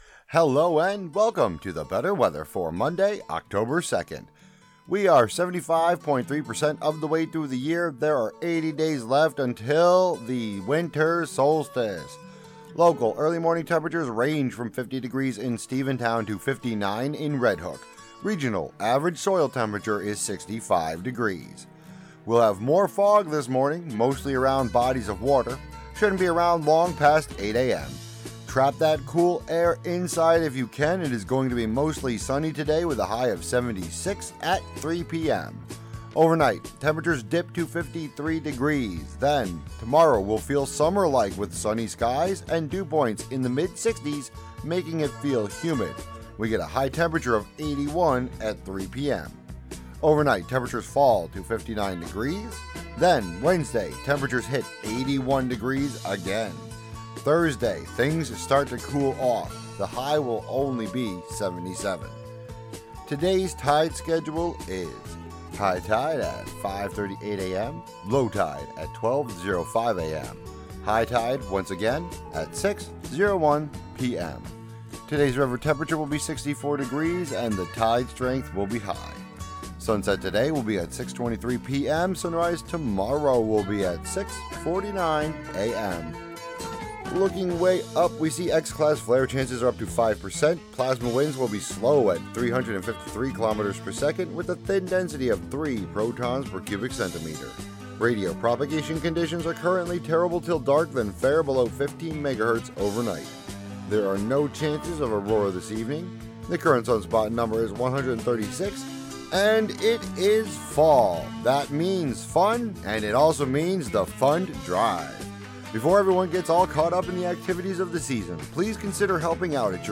Today's local weather.